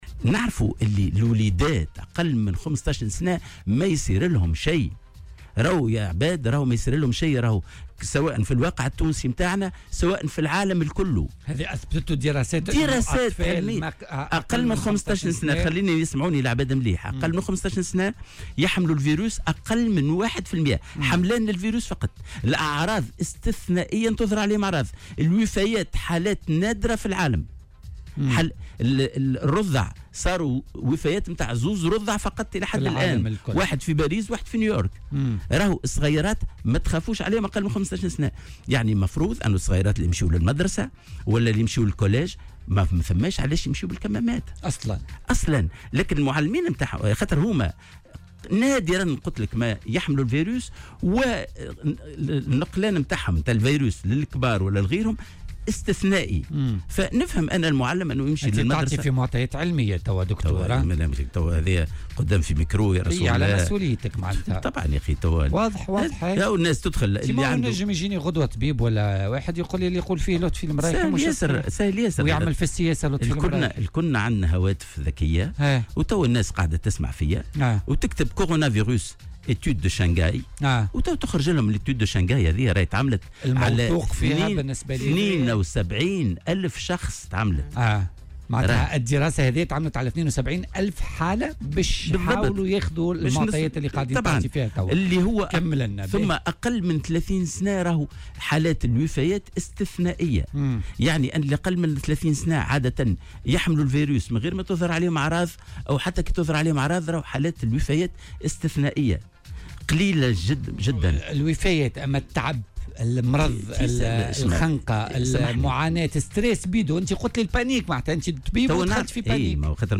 وأضاف في مداخلة له اليوم في برنامج "بوليتيكا" على "الجوهرة أف أم" أن عديد الدراسات في العالم أثبتت أن حالات الوفاة نادرة واستتثنائية في صفوفهم (وفاة رضعين اثنين في العالم منذ انتشار الوباء).وتابع أنه ما من موجب لارتداء الأطفال للكمامات، لأنهم نادرا ما يحملون الفيروس وتظهر عليهم أعراض، وفق قوله.وأوضح المرايحي أن قرار إيقاف الدروس غير صائب وأنه لا خوف للتلاميذ من الفيروس.